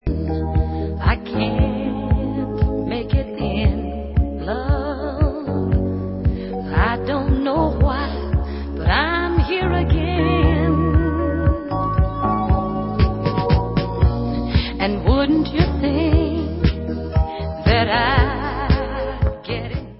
Dance/Soul